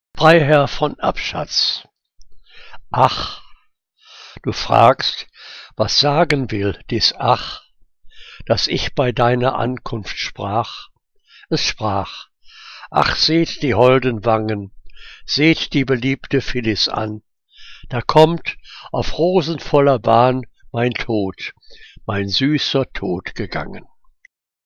Liebeslyrik deutscher Dichter und Dichterinnen - gesprochen (Abschatz)